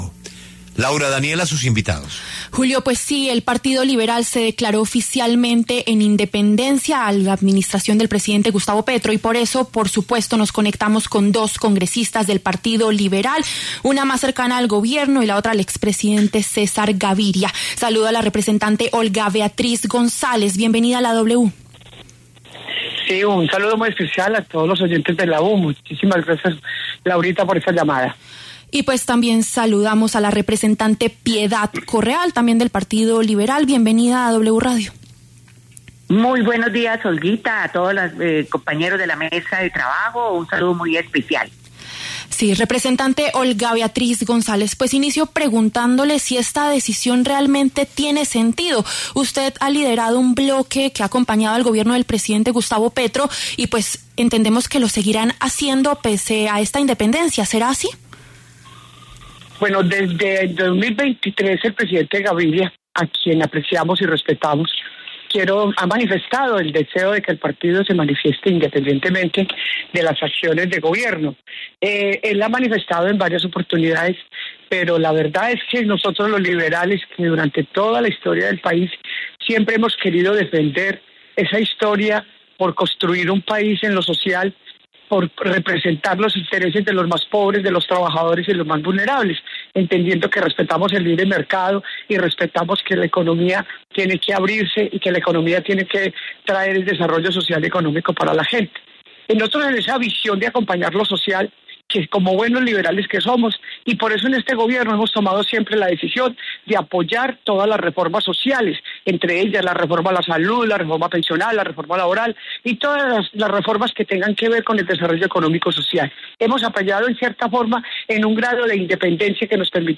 Las representantes Olga Beatriz González, afín al Gobierno, y Piedad Correal, cercana al expresidente Gaviria, debatieron en los micrófonos de La W sobre la decisión.